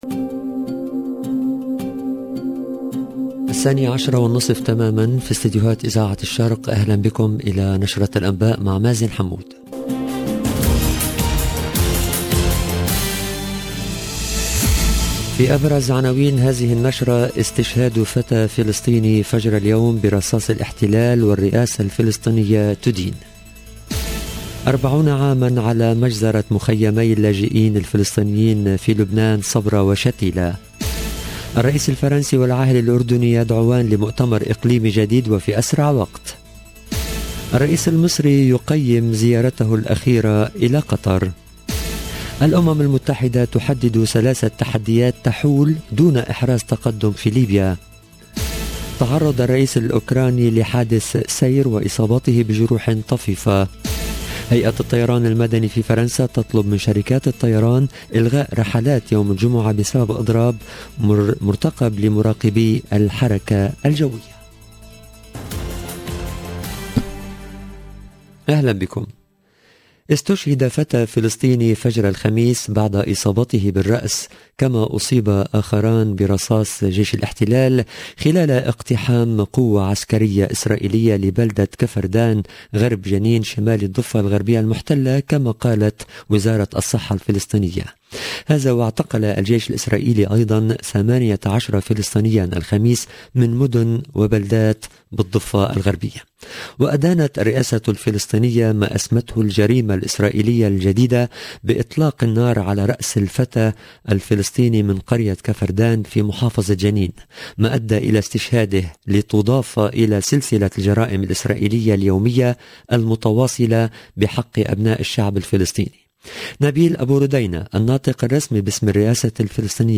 LE JOURNAL EN LANGUE ARABE DE MIDI 30 DU 15/09/22